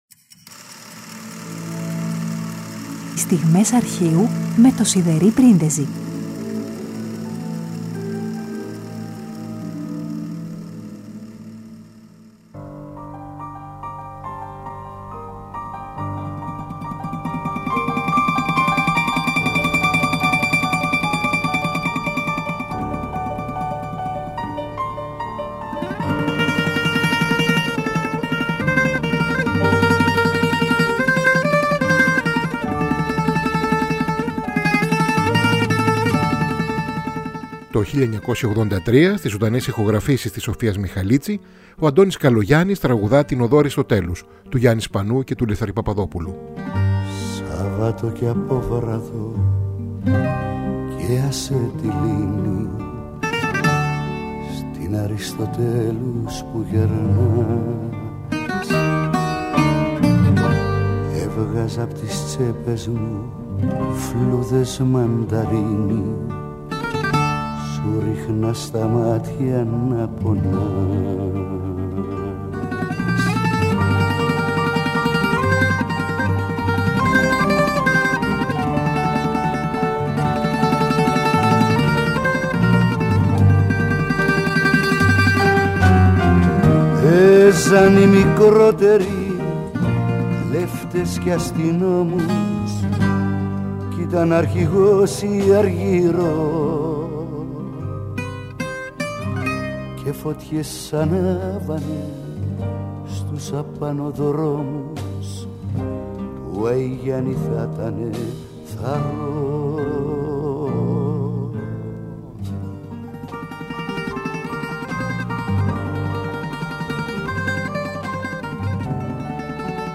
τραγούδια ηχογραφημένα στο ραδιόφωνο